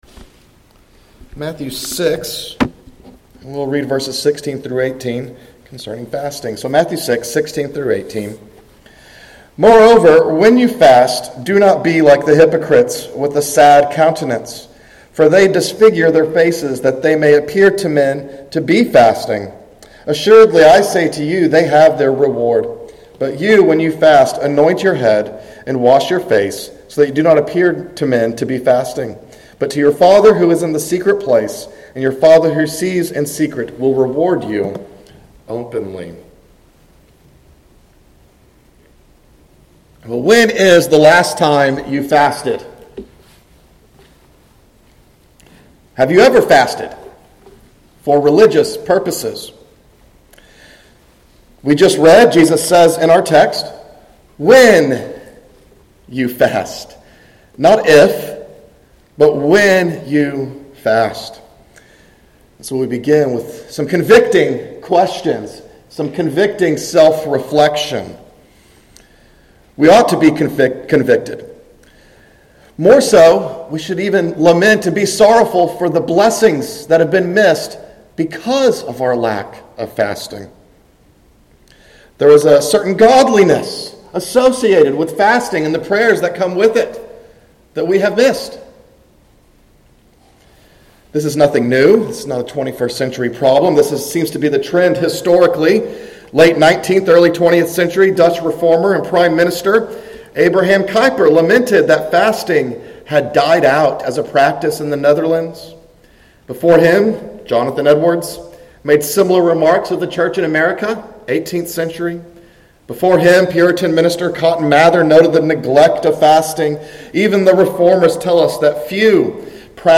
Afternoon Service